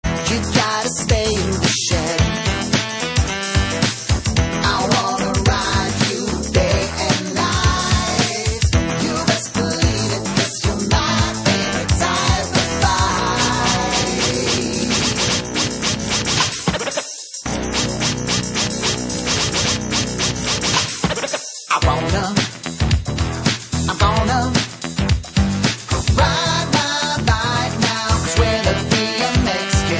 Your one-stop site for Commodore 64 SID chiptune remixes.